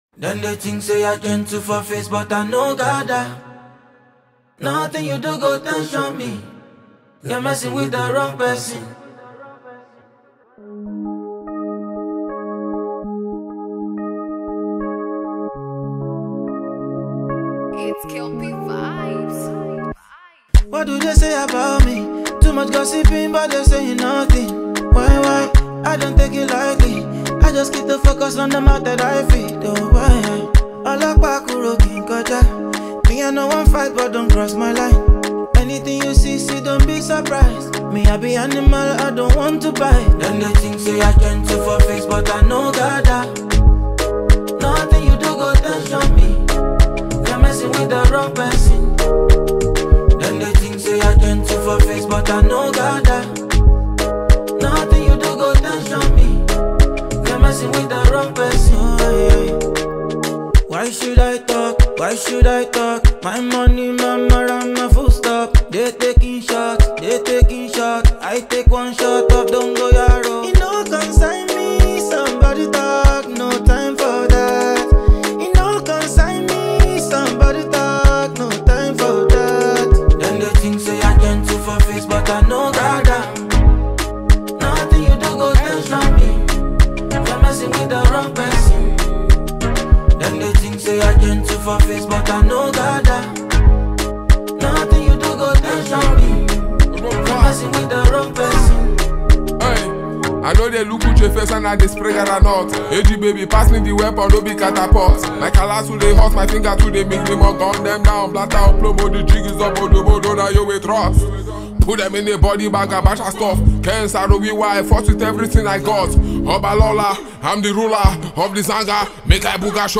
rapper and singer